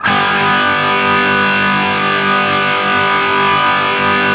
TRIAD G  L-L.wav